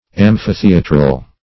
Search Result for " amphitheatral" : The Collaborative International Dictionary of English v.0.48: Amphitheatral \Am`phi*the"a*tral\, a. [L. amphitheatralis: cf. F. amphith['e][^a]tral.]